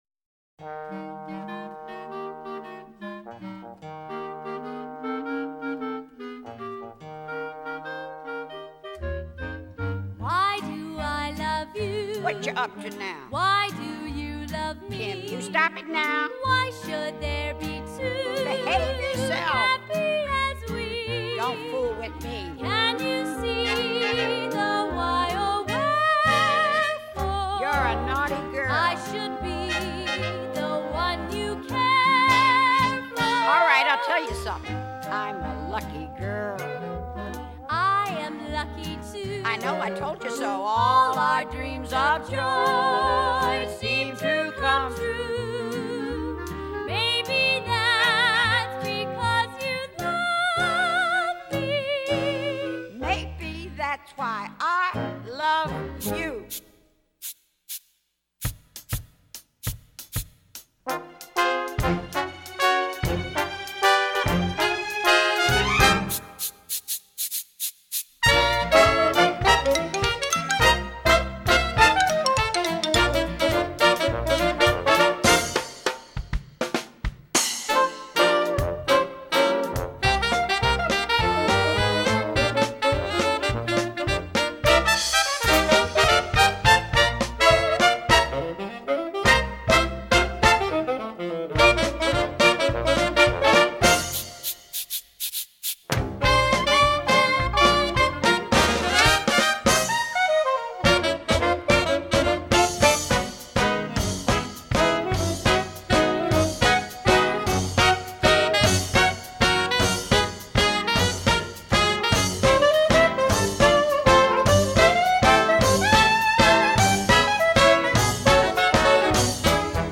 rousing and syncopated charleston tableau